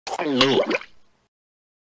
Gulp Sound Effect: Unblocked Meme Soundboard